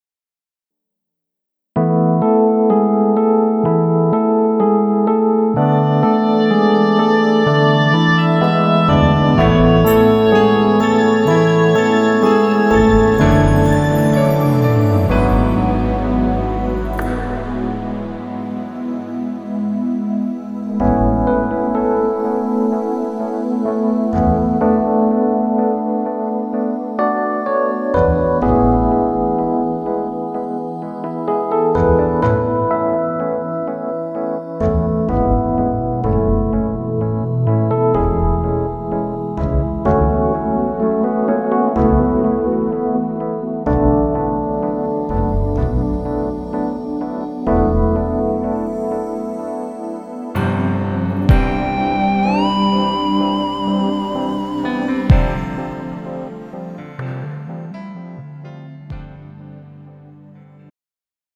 음정 -3키
장르 축가 구분 Pro MR